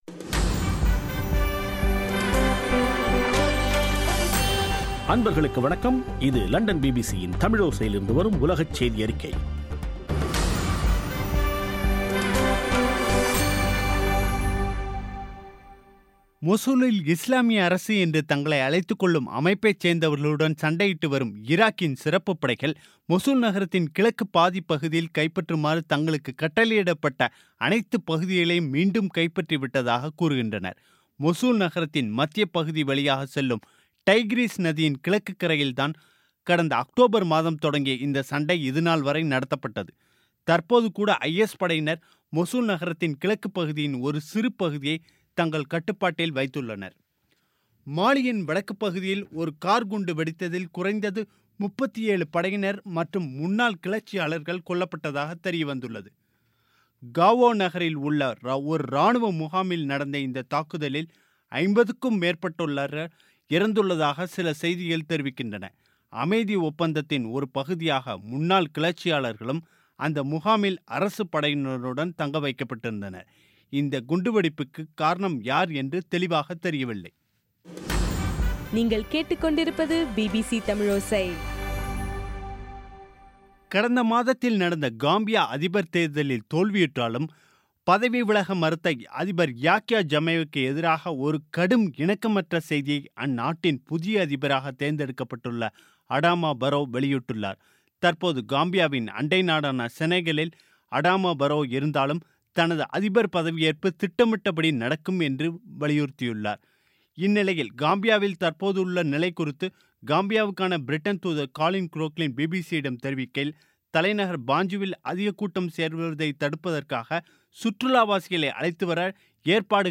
பிபிசி தமிழோசை செய்தியறிக்கை (18/01/2017)